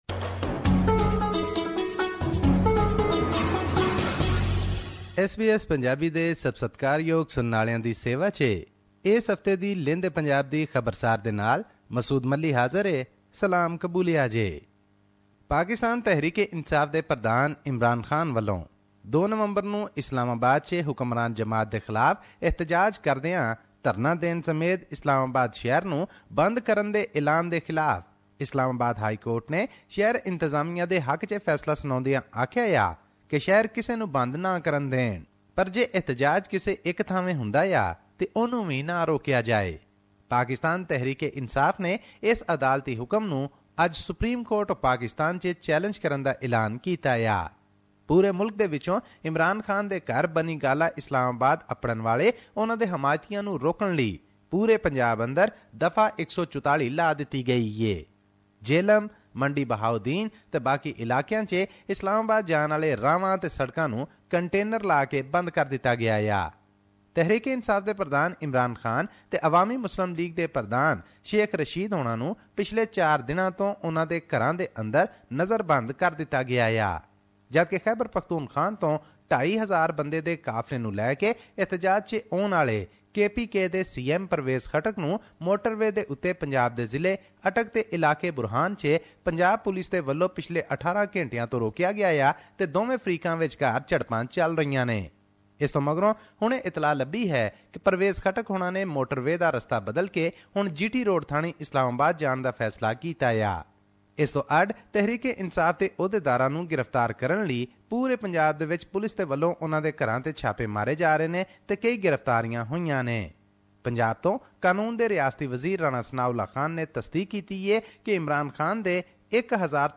Punjabi News